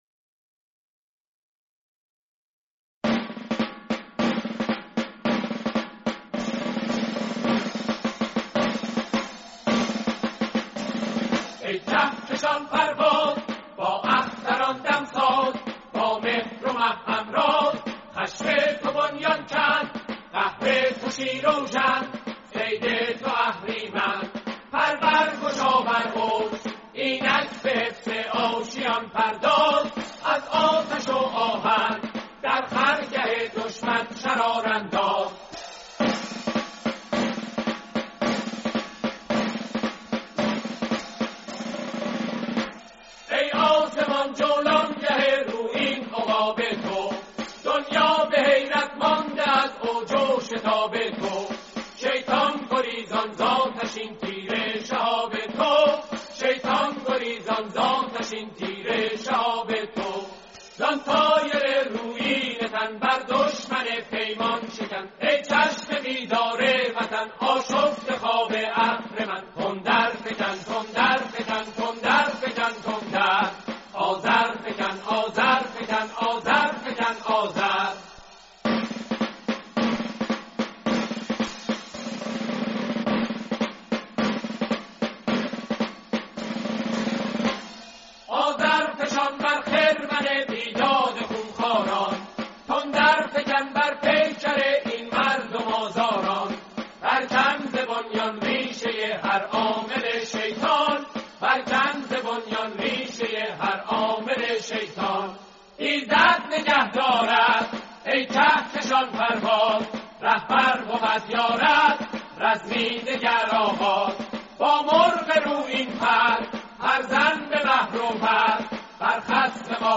سرودی با اجرای گروه کر و سازهای کوبه ای
شیوه اجرا: جمع خوانی